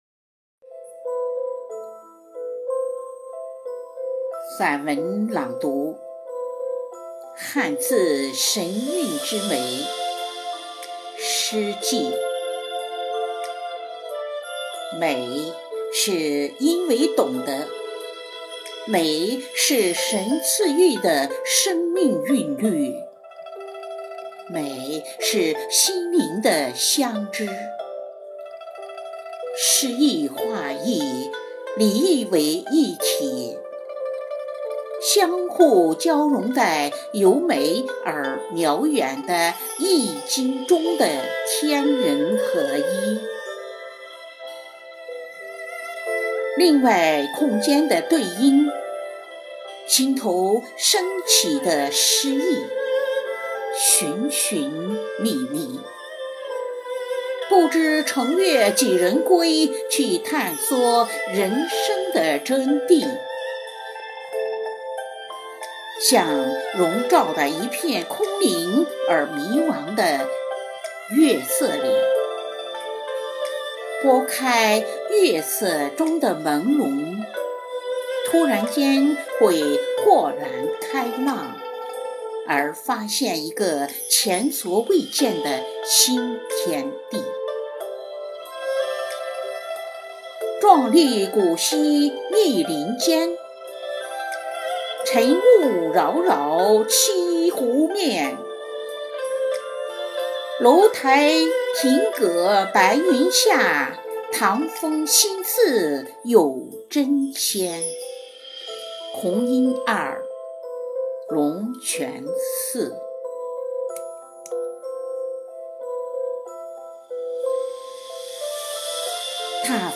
配樂散文朗誦（音頻）：漢字神韻之美—詩跡 | 法輪大法正見網
配樂: 二胡與琵琶重奏：登山臨水
因為是手機錄音，一氣呵成，可能不夠標準，也沒經過剪裁，還望編輯矯正編剪。